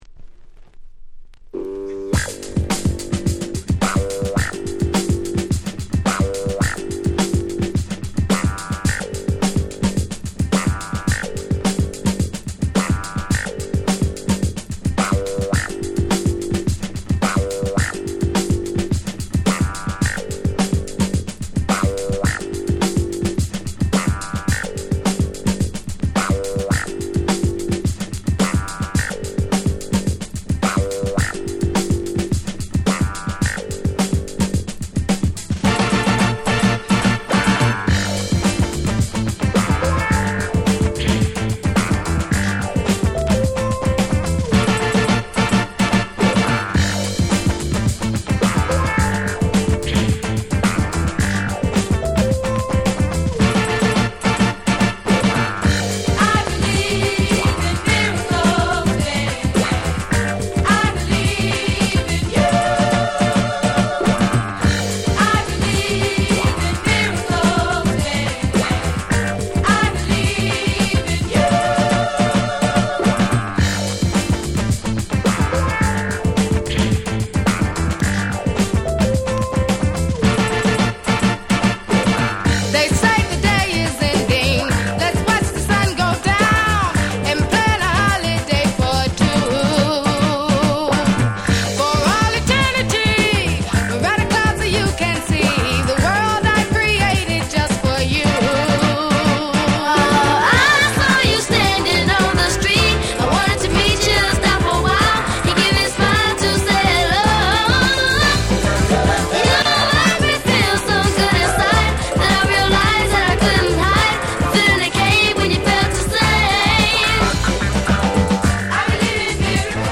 鉄板Dance Classic !!
音質もバッチリなので、Play用にはコチラで十分かと。